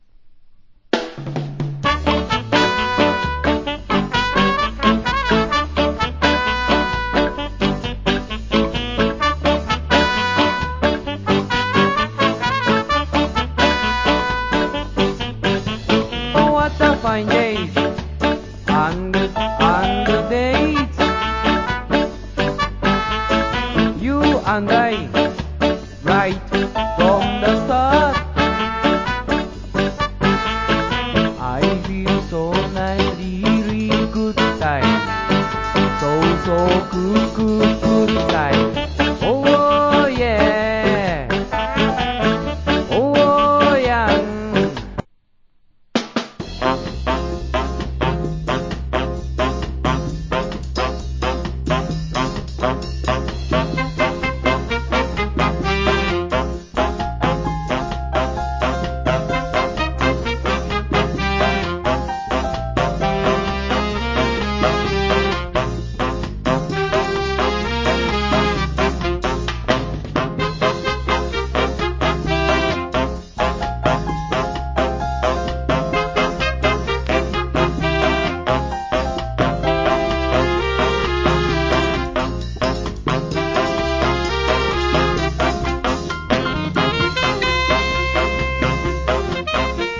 Good Ska Vocal.